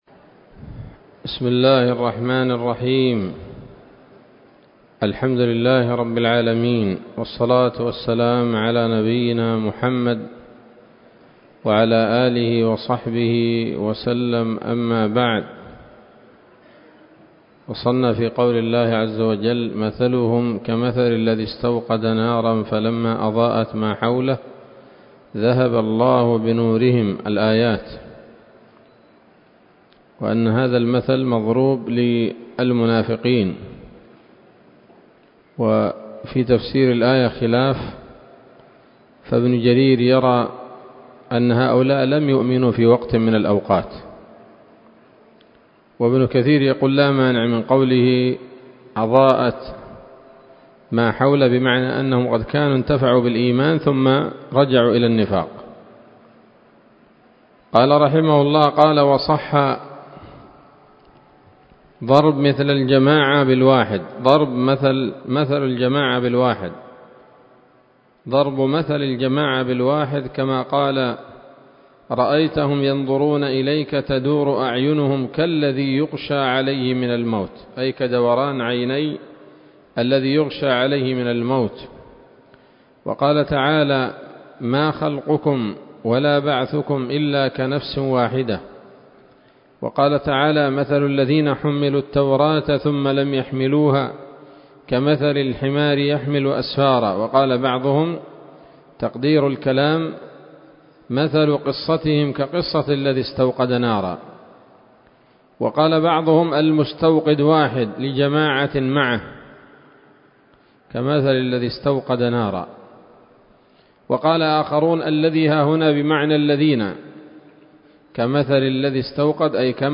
الدرس العشرون من سورة البقرة من تفسير ابن كثير رحمه الله تعالى